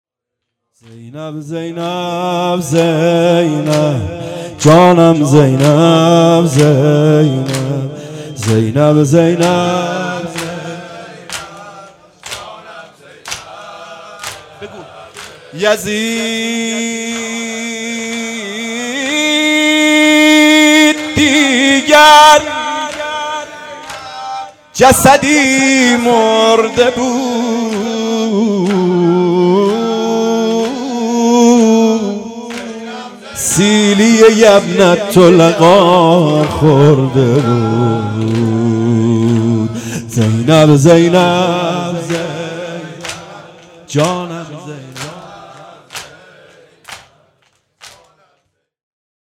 شب چهارم محرم الحرام 1441